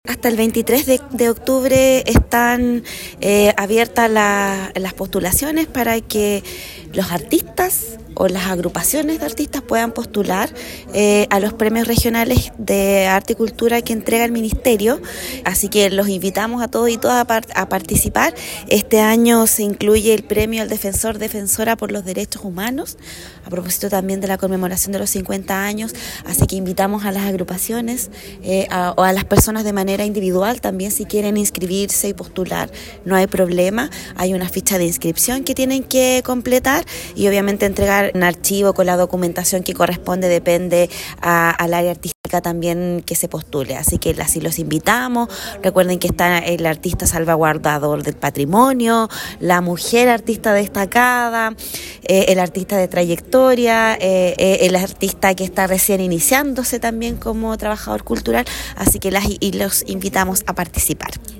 El objetivo de esta instancia es valorar y visibilizan la contribución de creadores y cultores al desarrollo de las expresiones artísticas en nuestra región, los antecedentes de las y los candidatos que reúnan los méritos, según lo establecido en las bases, deberán ser presentados por una tercera persona, se  natural o jurídica, como lo explicó la Secretaria Regional Ministerial de las Culturas, las Artes y el Patrimonio, Cristina Añasco.